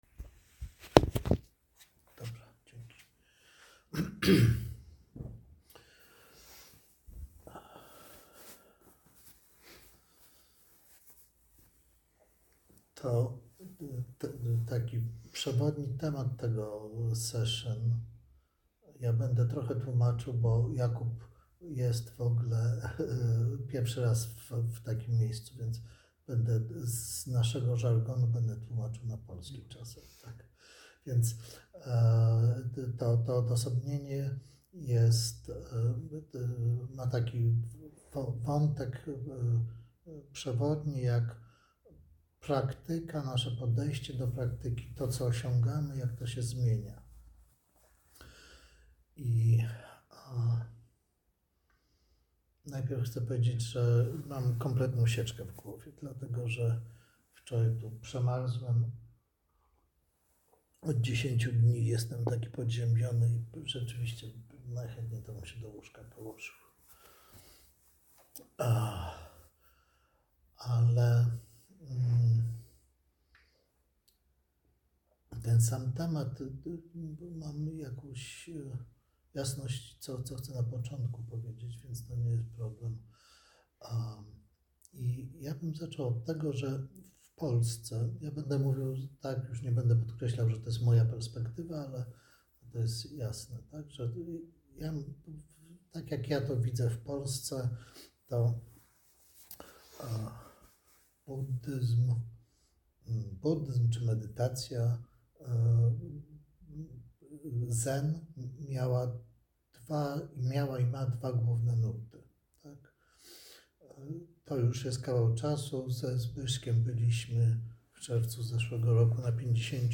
Mowa